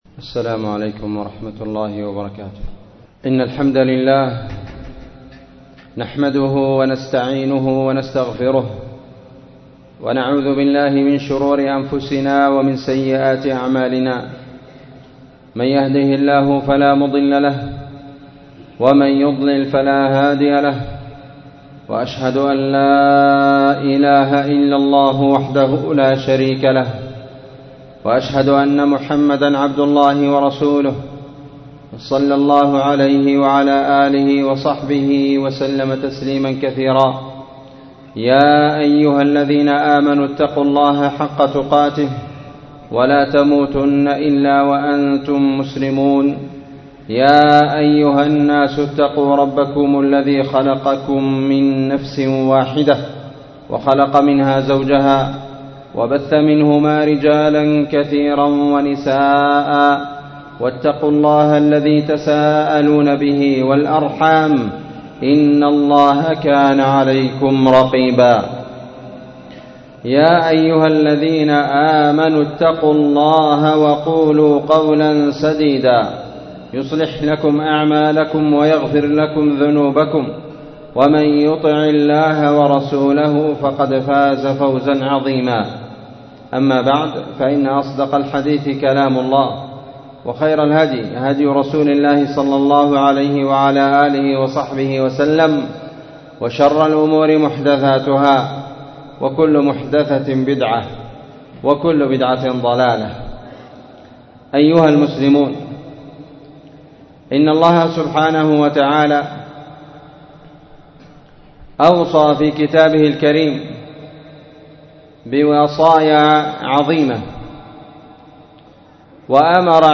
عنوان فضل النصيحة وأهميتُها تاريخ النشر 2021-10-17 وصف خطبة الجمعة ????
مسجد المجاهد مسجد أهل السنة والجماعة تعز _اليمن ????